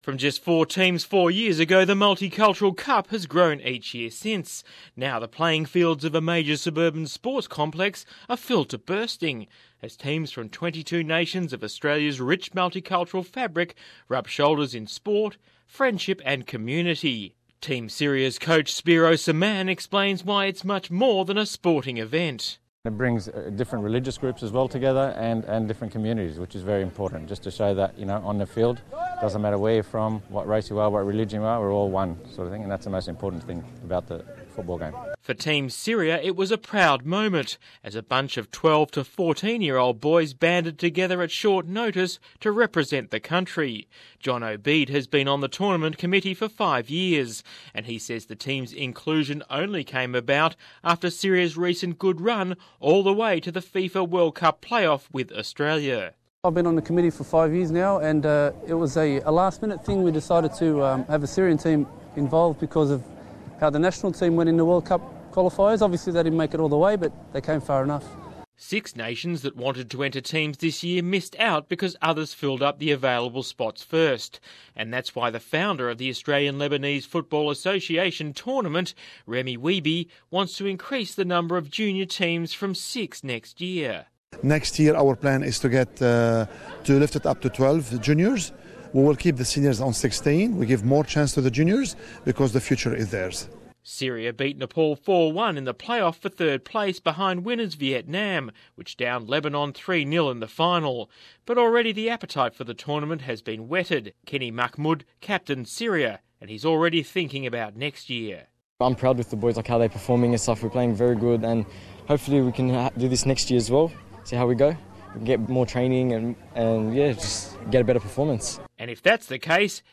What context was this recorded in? Team Syria in full voice at western Sydney's multicultural cup Source: SBS